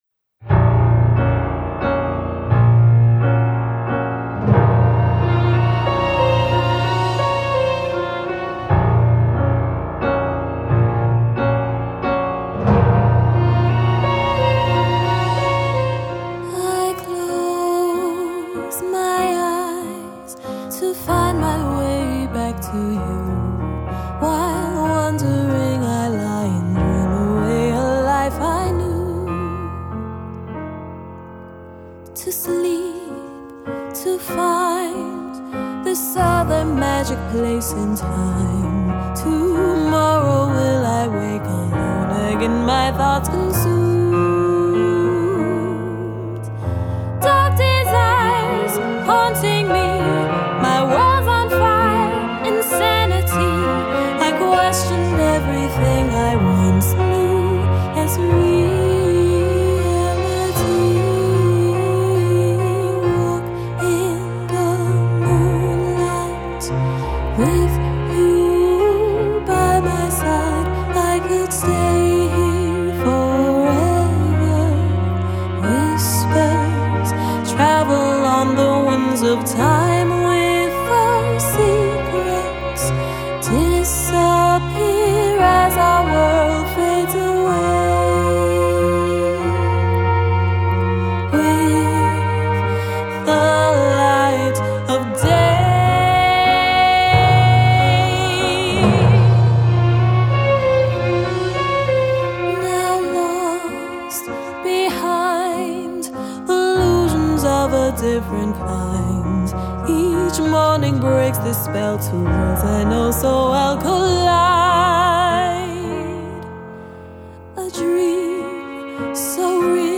A Gothic Drama
Solo violin and viola